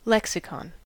lexicon-us.mp3